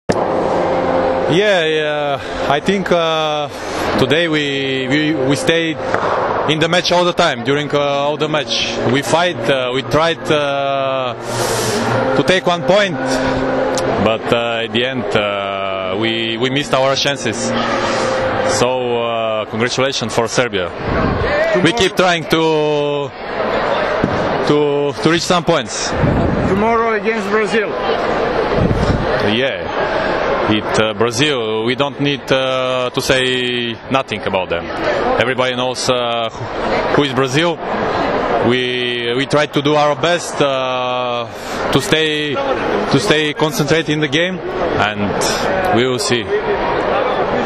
IZJAVA VIKTORA JOSIFOVA